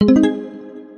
pickup.wav